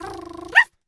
pgs/Assets/Audio/Animal_Impersonations/dog_2_small_bark_07.wav at master
dog_2_small_bark_07.wav